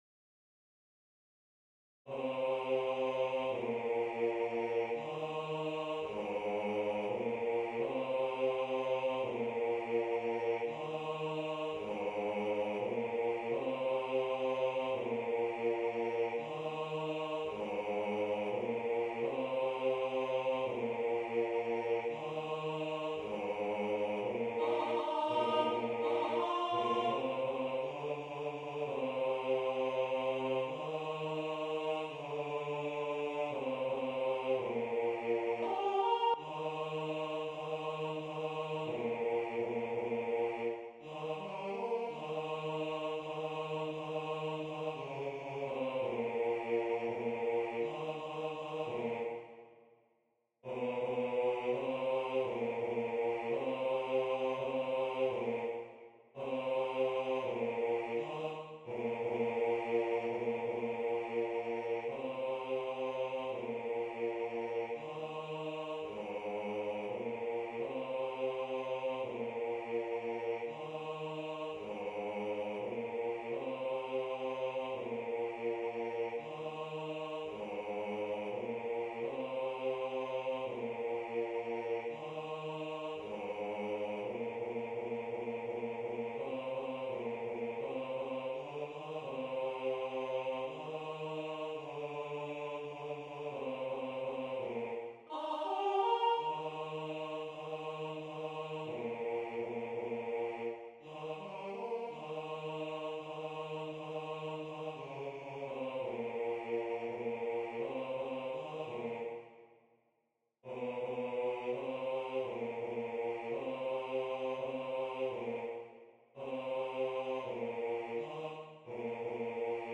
bas - computerstem